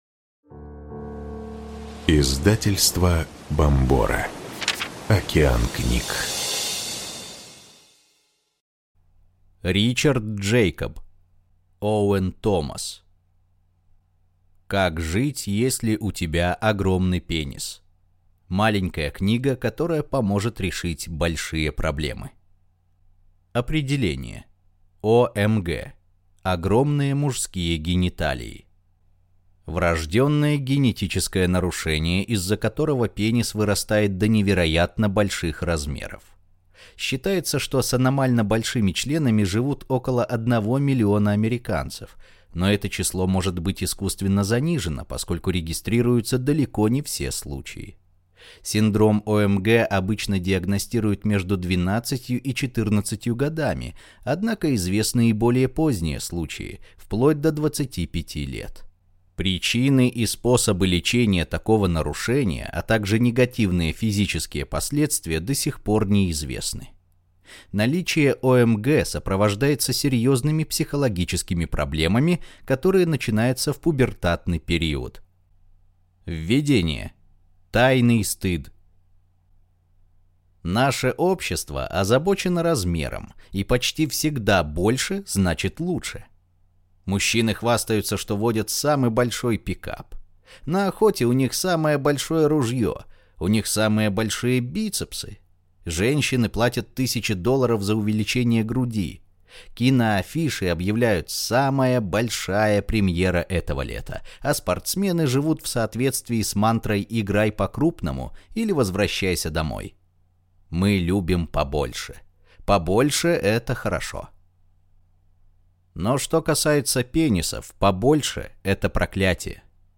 Аудиокнига Как жить, если у тебя огромный пенис. Маленькая книга, которая поможет решить большие проблемы | Библиотека аудиокниг